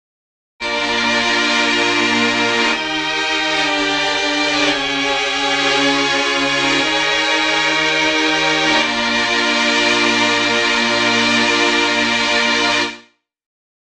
03-Novatron Novalins